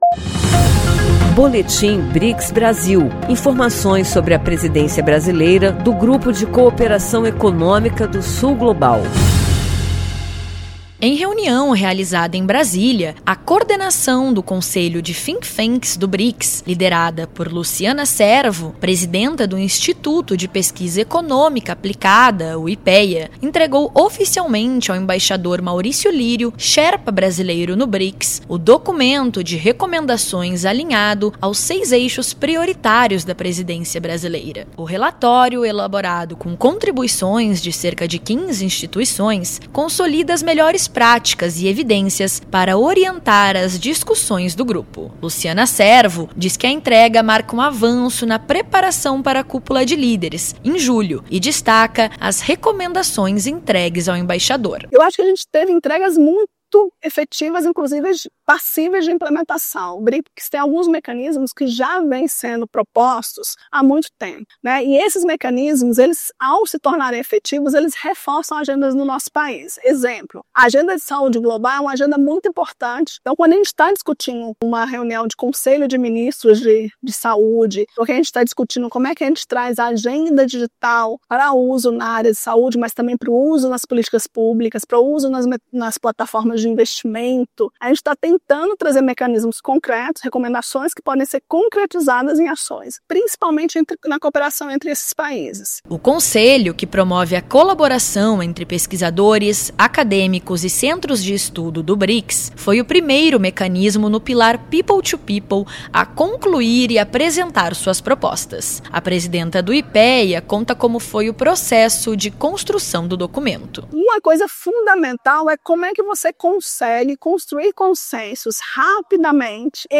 O IBGE coordenou, nos dias 17 e 18 de fevereiro, a reunião dos INEs do BRICS para preparar a Publicação Estatística Conjunta de 2025, discutindo indicadores e dados para a Cúpula de Chefes de Estado. Ouça a reportagem e saiba mais.